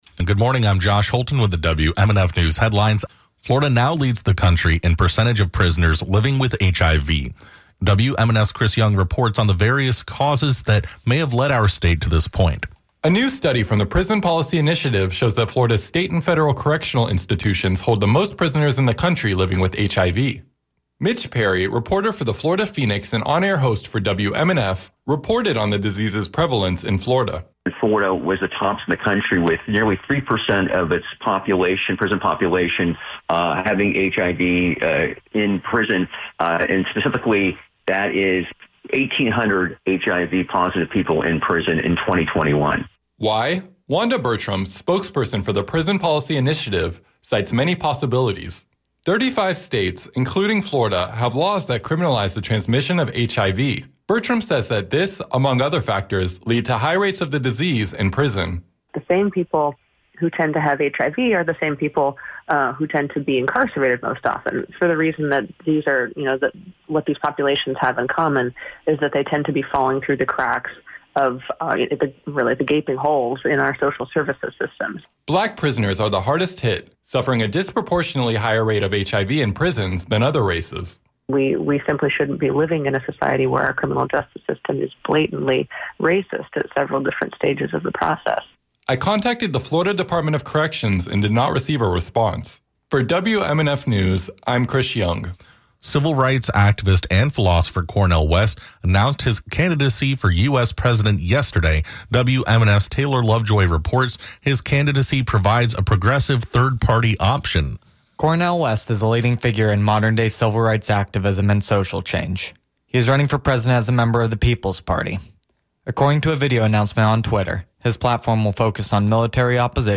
The Scoop: WMNF’s daily digest of news headlines for Tuesday, June 6th, 2023